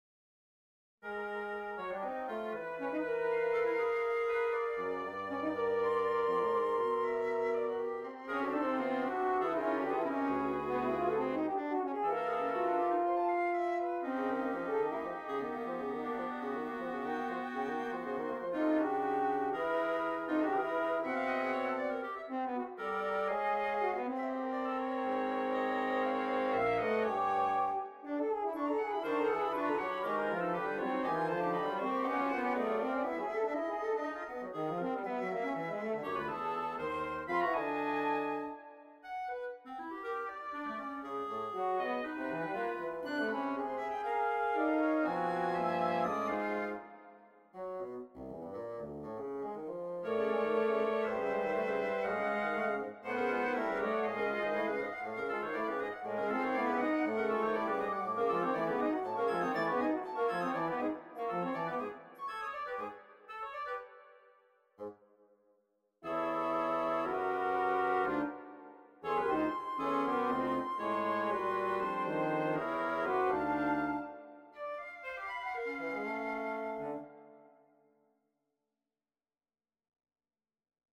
on a randomly-generated tone row
for Flute, Oboe, Clarinet, Bassoon, and Horn
HarmonicEtudeNo3.mp3